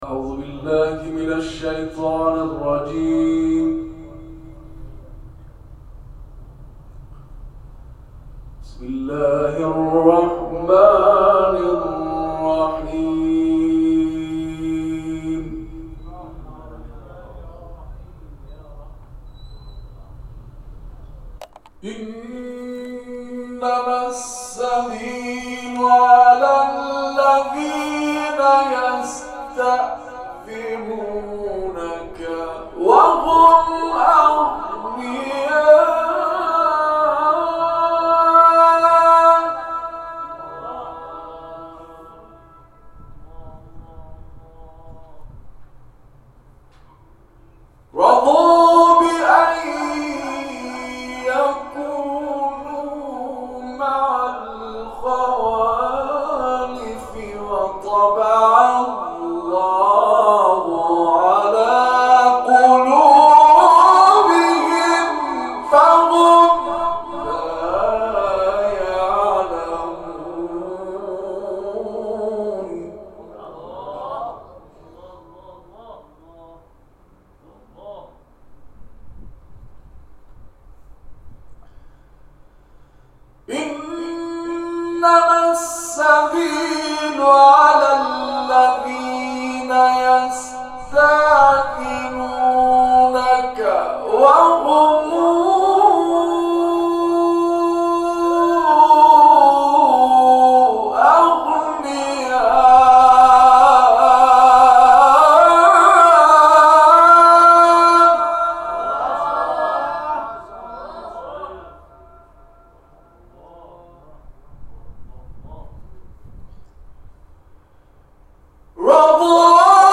Quran dinlə - Məşhur İranlı qarinin nadir tilavətlərindən